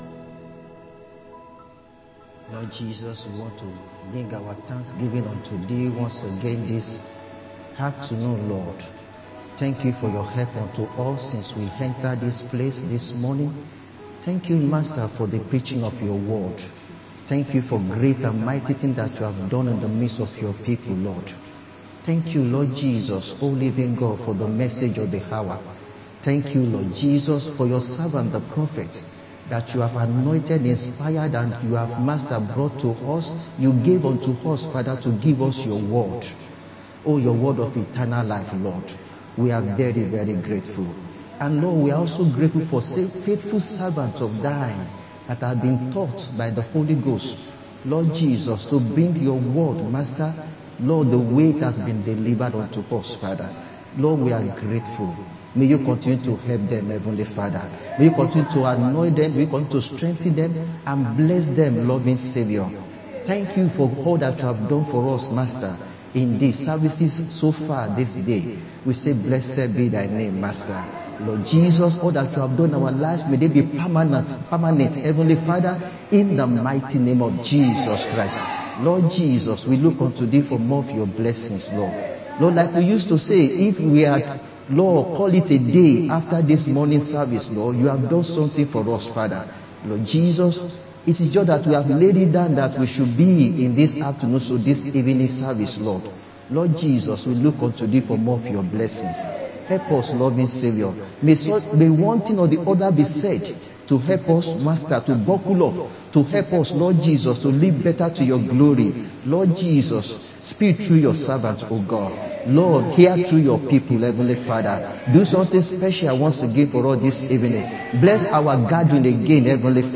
Sunday Afternoon Service